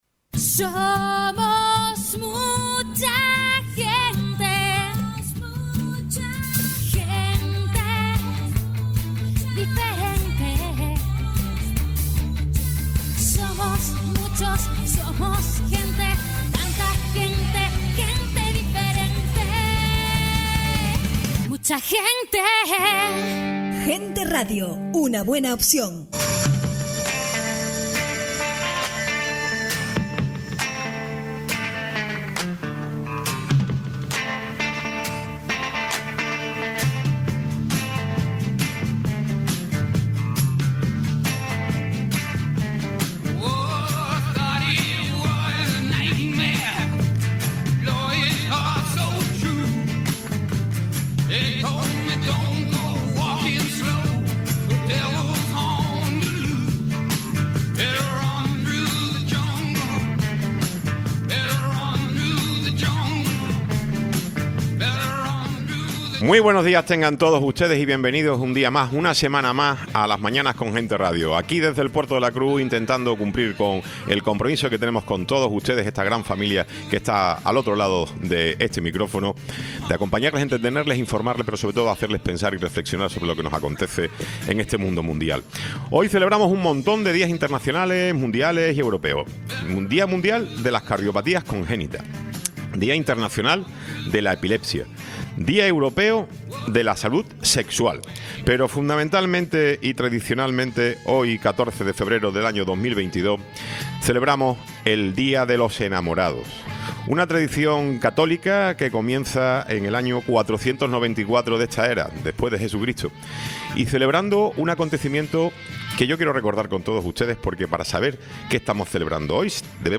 Tiempo de entrevista con José Alberto León, director insular de Movilidad del Cabildo de Tenerife